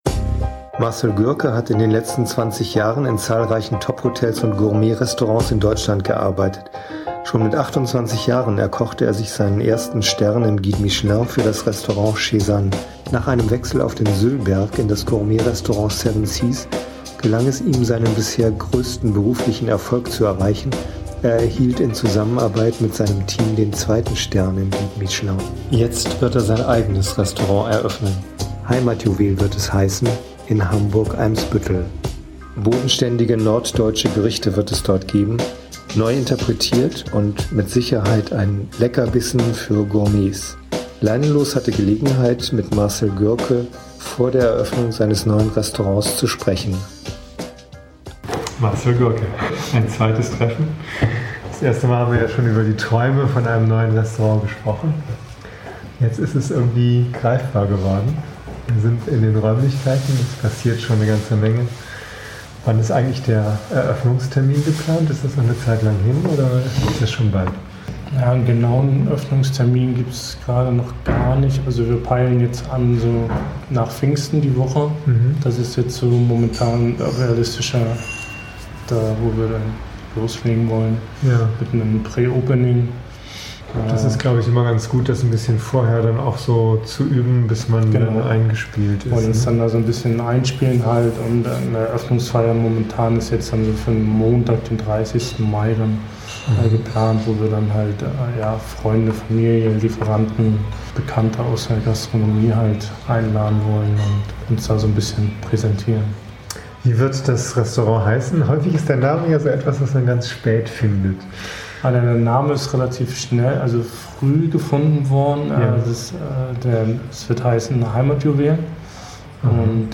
Hintergund-Musik bei Intro und Abspann